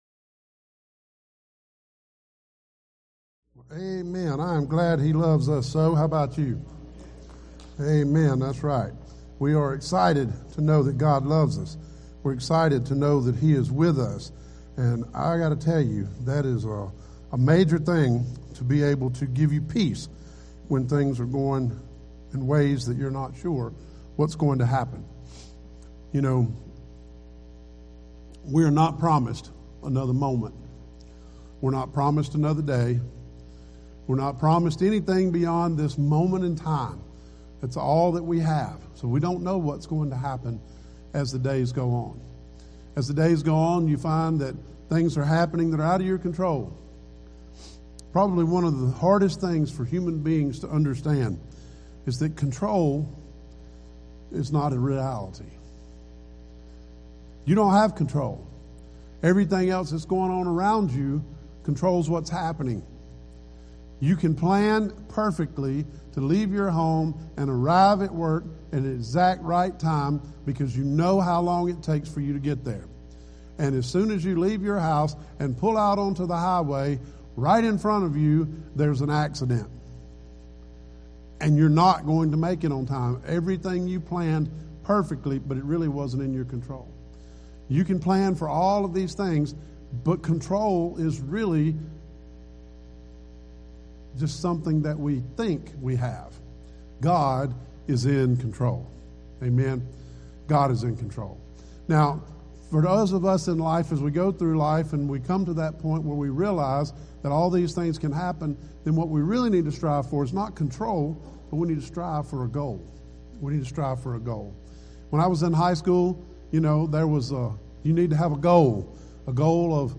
Sermons Archive ⋆ Orchard Baptist Church